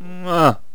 khanat-sounds-sources/sound_library/voices/death/haaaaaa/archer_die2.wav at f42778c8e2eadc6cdd107af5da90a2cc54fada4c
archer_die2.wav